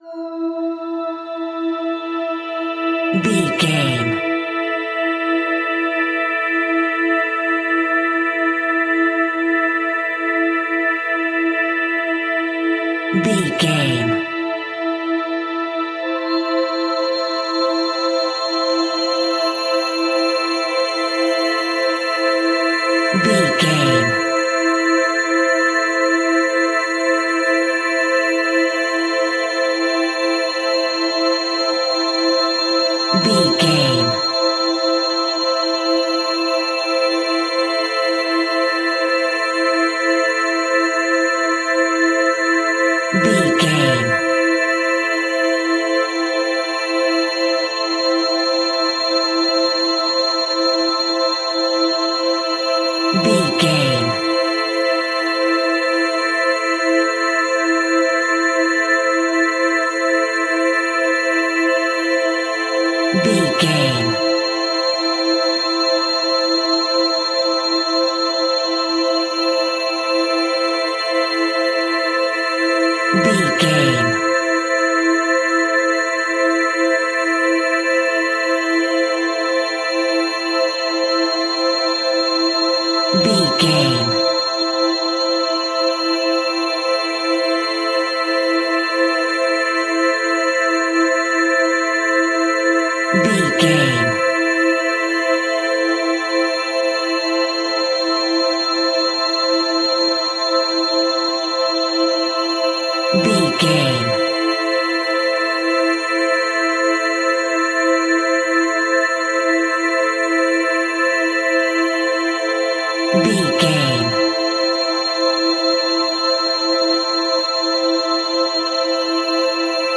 Aeolian/Minor
scary
ominous
dark
suspense
haunting
eerie
synthesizer
Synth Pads
Synth Strings
synth bass